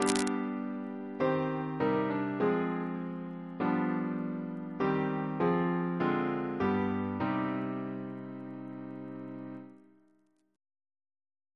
CCP: Chant sampler
Single chant in C minor Composer: Sir H. Walford Davies (1869-1941), Organist of the Temple Church and St. George's, Windsor Reference psalters: ACB: 327